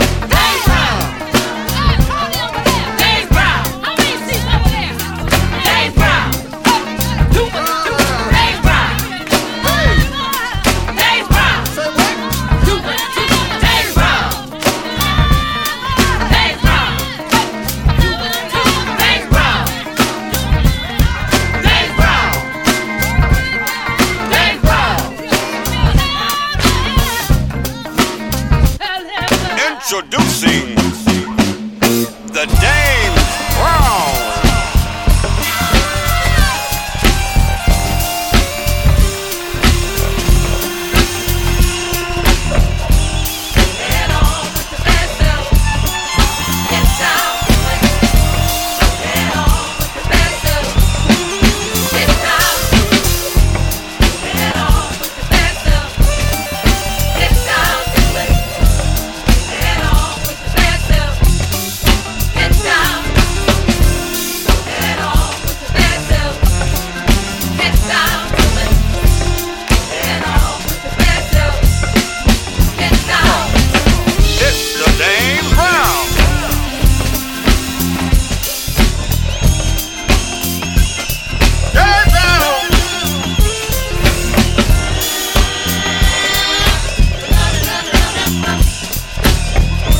ジャンル(スタイル) FUNK / SOUL / NU DISCO